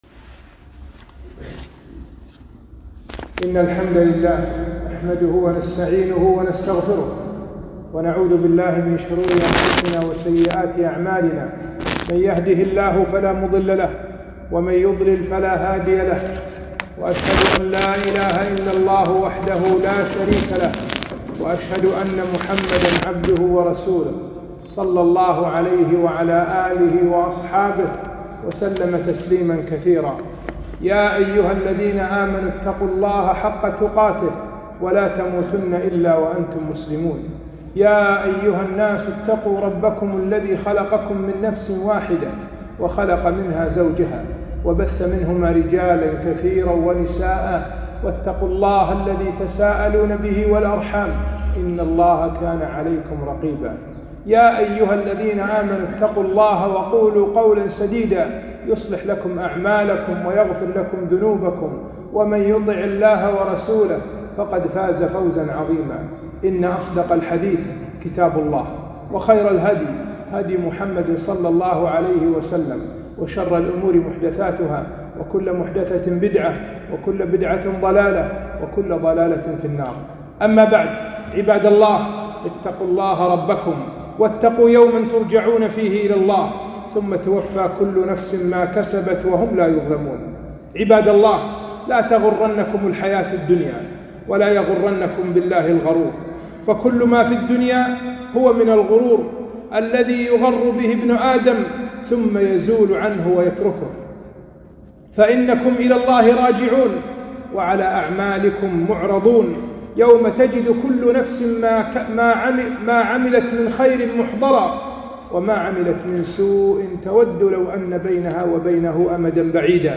خطبة - نعمة الوطن